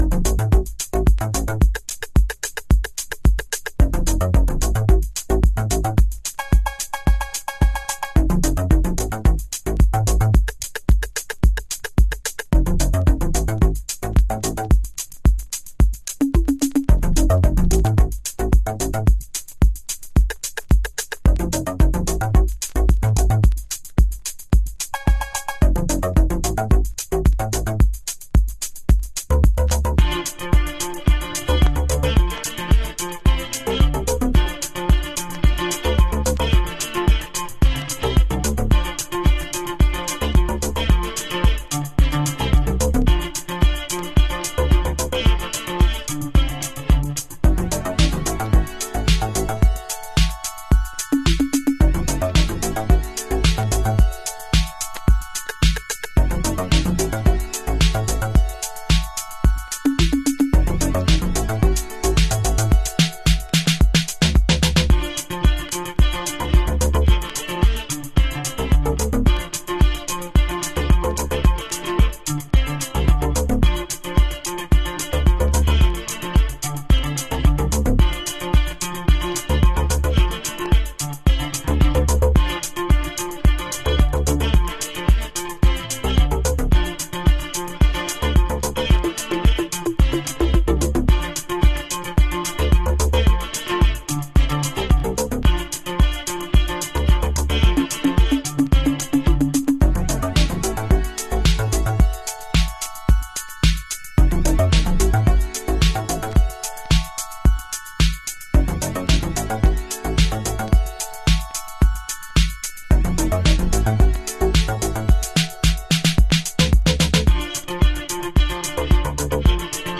House / Techno
Instrumental